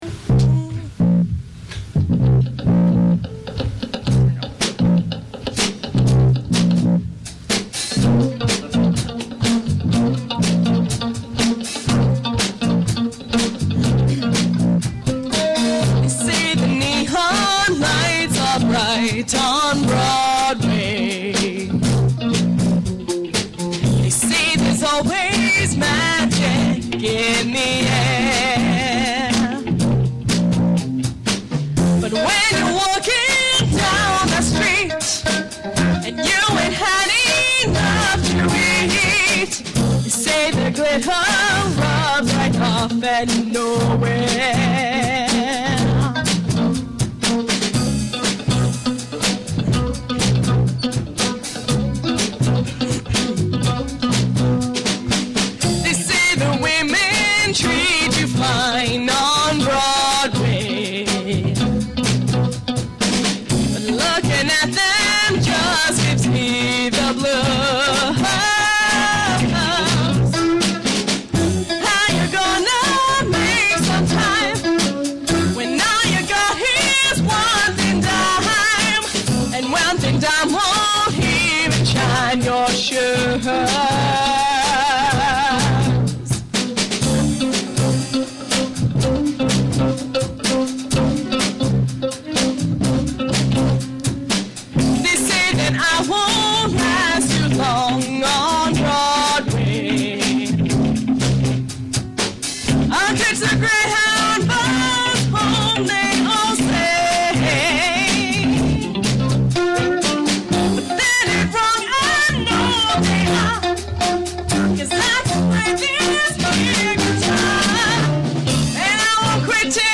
la f�te de la musique
It's saturated beyond recognition